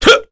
Chase-Vox_Jump_kr.wav